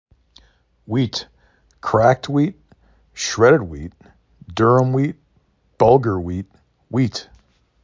w E t
hw E t